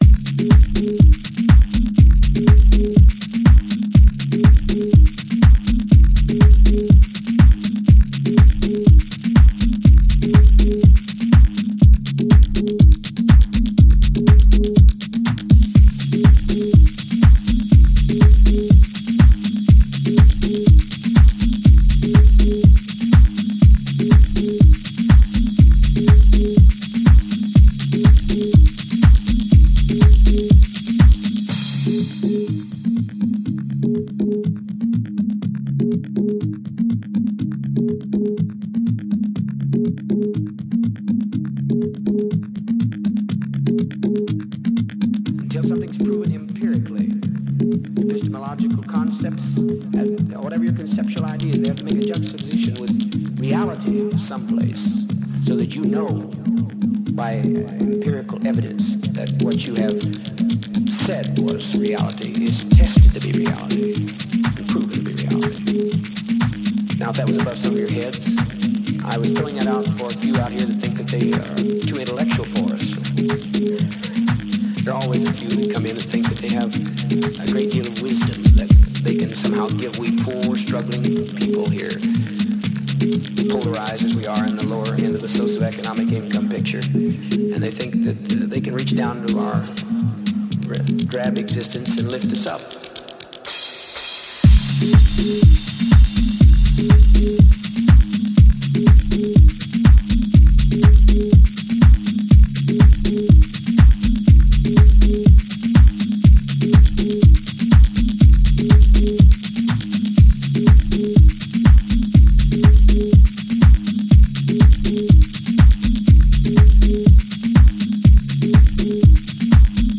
reworking some house classics and underground gems